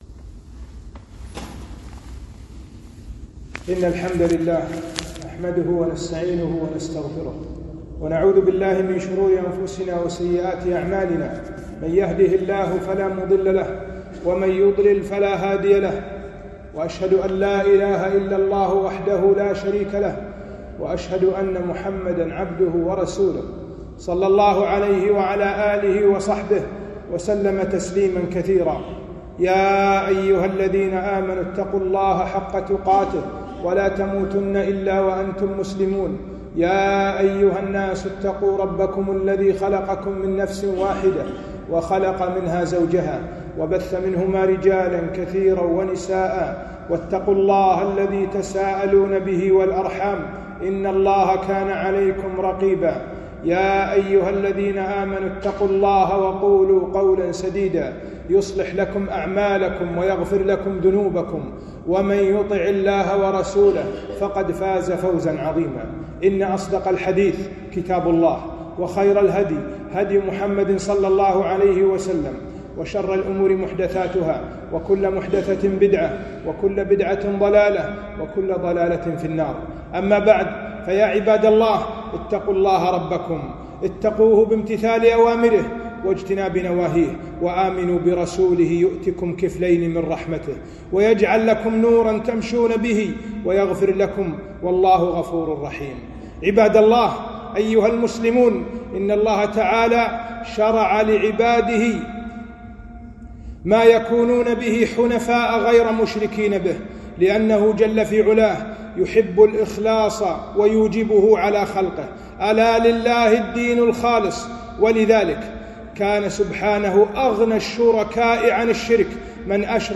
خطبة - زيارة القبور بين المشروع والممنوع